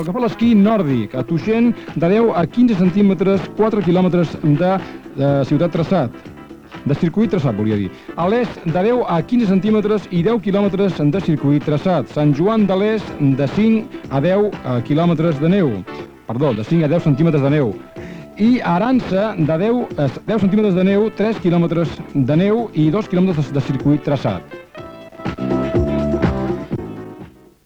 Estat de la neu en algunes de les estacions d'esquí catalanes Gènere radiofònic Esportiu